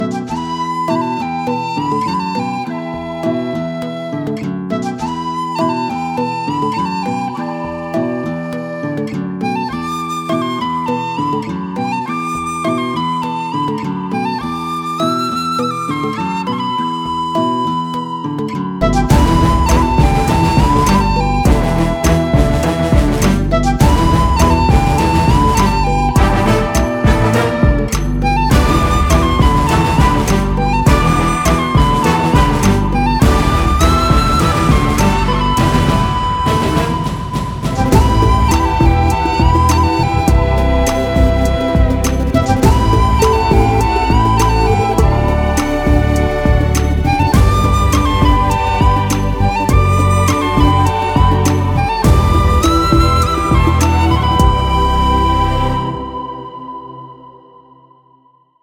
Song instrumental with perfect sync without lyrics portion.